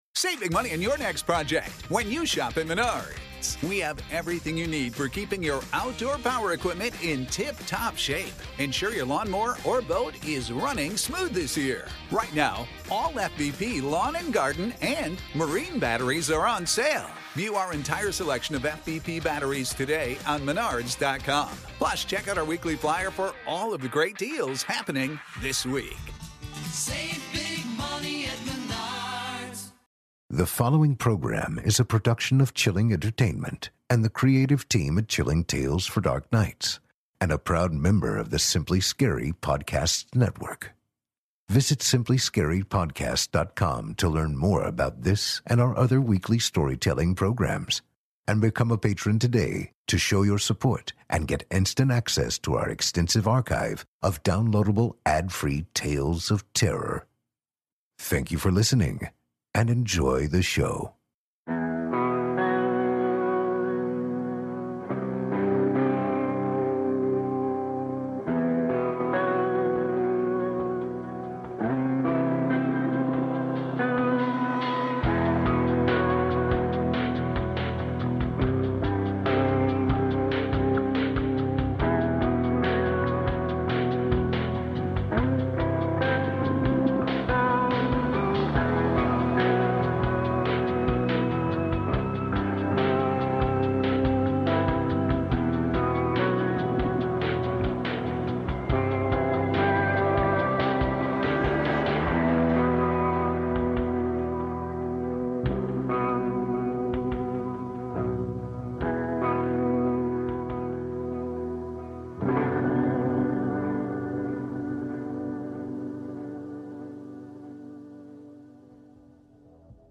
Tonight, we’re going to be reading a tale from Sam Morris, who we featured a few weeks back with his story, "Mammaw,” and last season with “Hollow.”